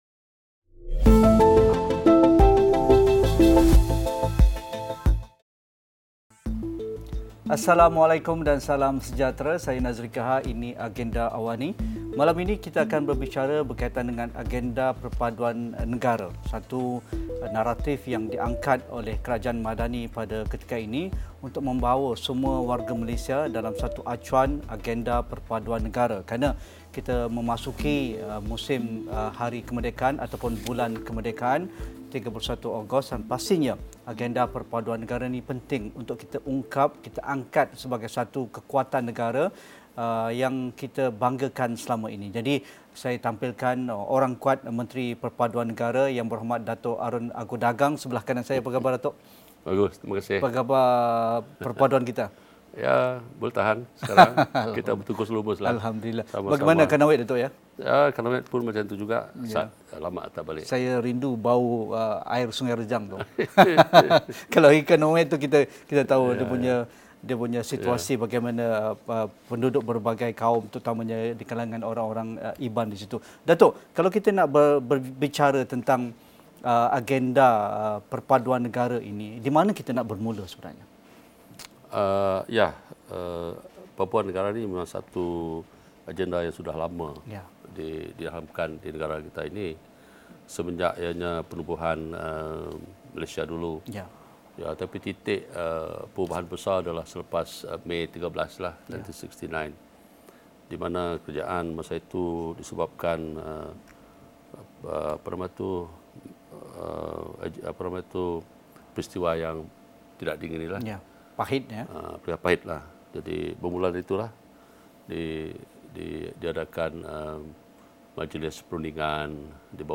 Temu bual khas bersama Menteri Perpaduan Negara, Datuk Aaron Ago Dagang.